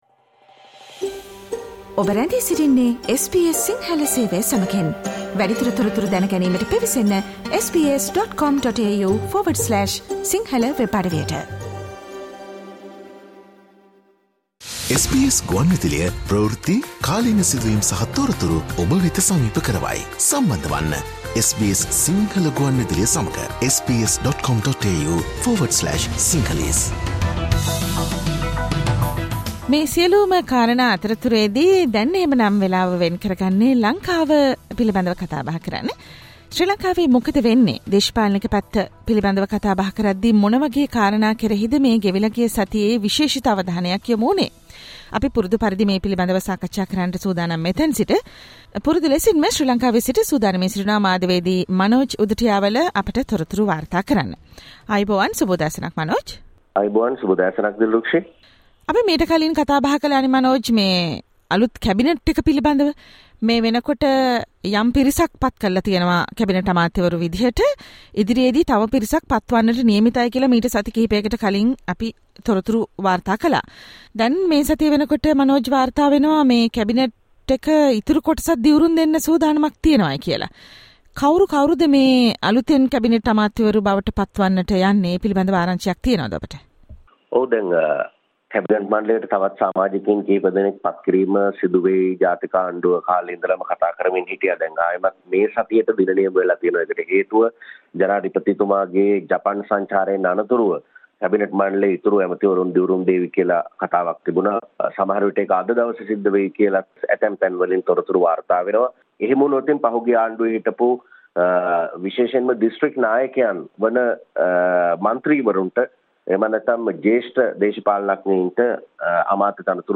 Sri Lankan political wrap of the week from SBS Sinhala Radio_ Australia
SBS Sinhala radio brings you the most prominent news highlights of Sri Lanka in this featured current affair segment every Monday